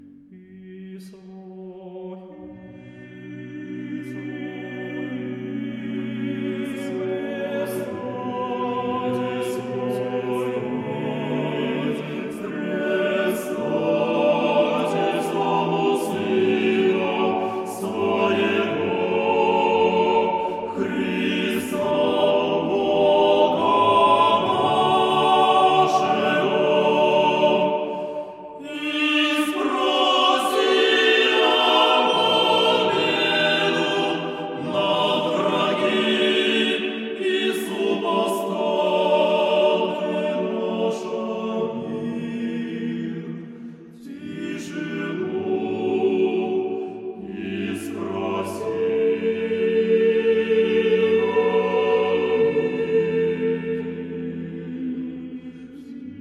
dans les églises, des choeurs de prêtres orthodoxes font la promo de leurs CD en chantant a capella des hymnes religieux : c'est très très beau et les touristes sont scotchés quelques instants au point d'en oublier leur appareil photo (pour vous, un extrait
orthodoxe